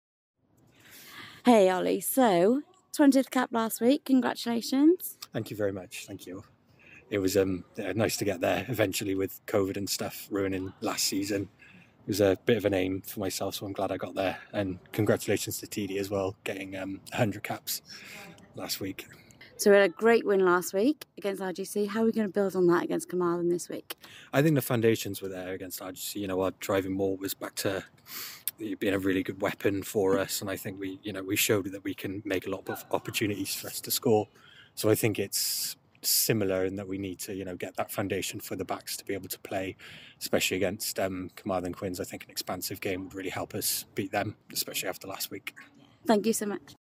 Pre-Match Interview